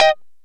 Lng Gtr Chik Min 08-C3.wav